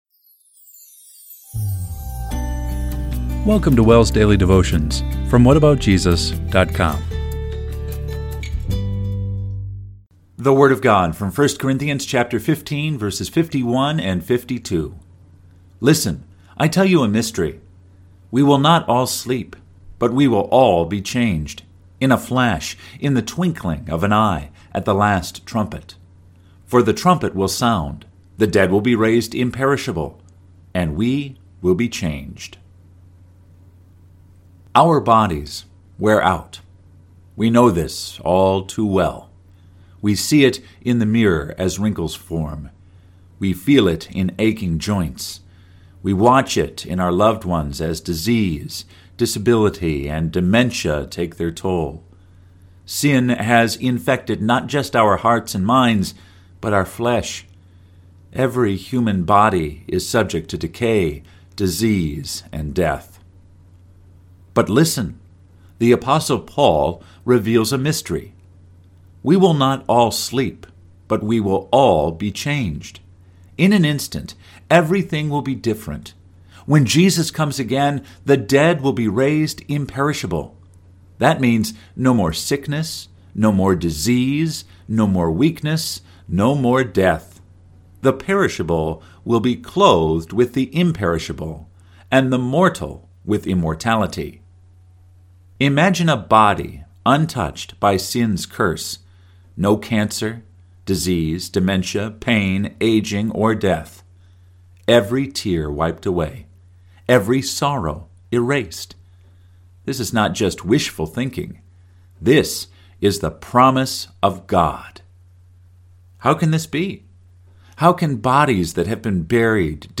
Daily Devotion – April 22, 2025